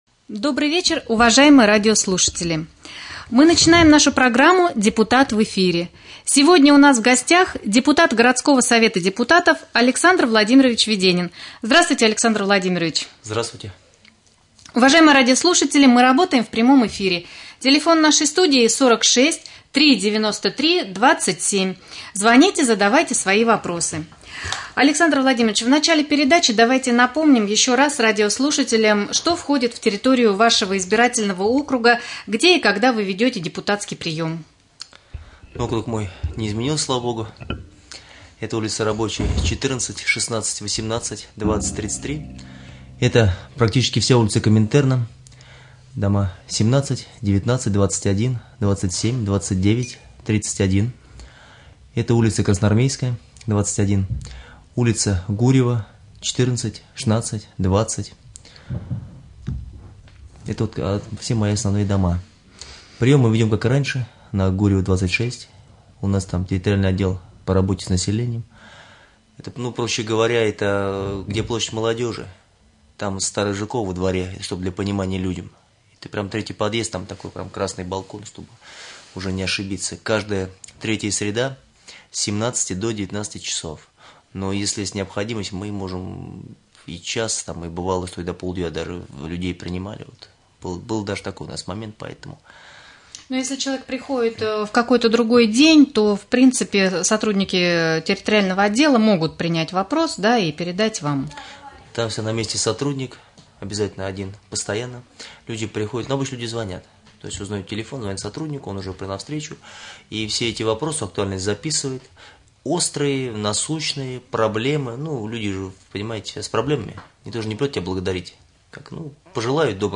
Прямой эфир. «Депутат в эфире». Гость студии депутат Совета депутатов городского поселения Раменское Александр Владимирович Веденин.